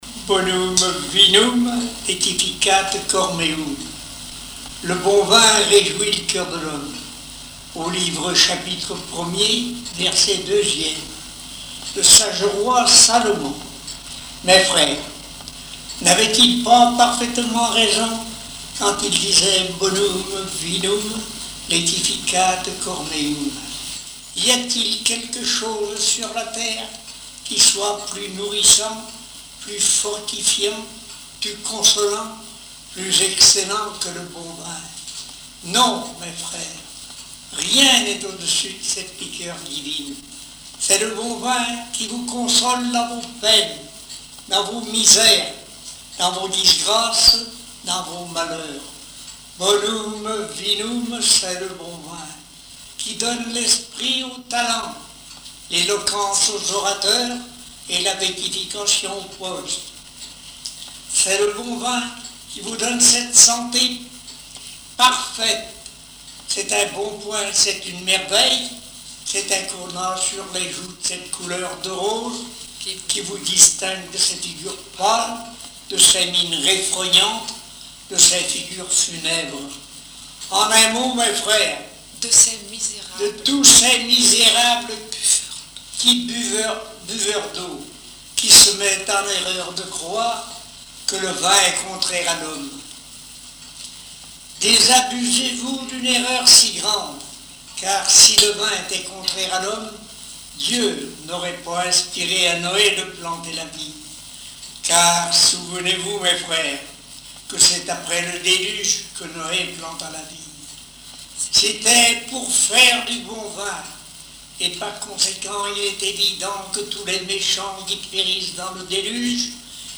circonstance : bachique
Genre strophique
Pièce musicale inédite